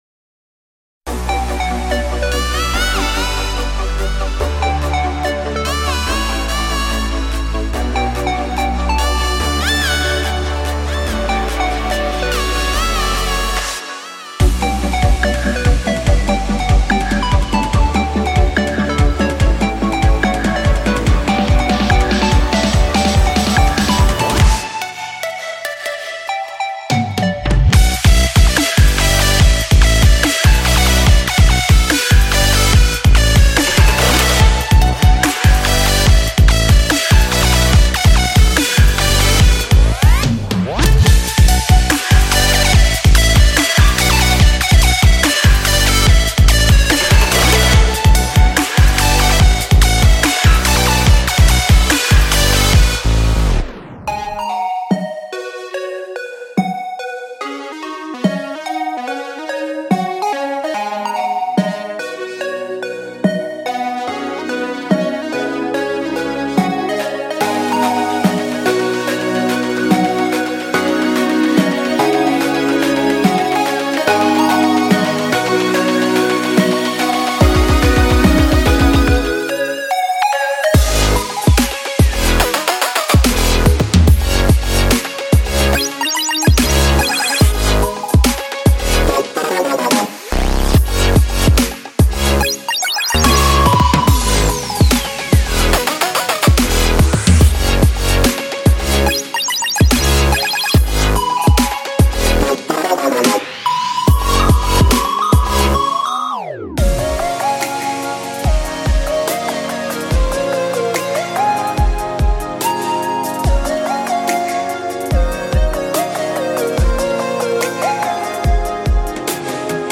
未来低音采样包
并以俏皮的声音设计为主导。
128个Future Bass血清预设和5个完整的构建套件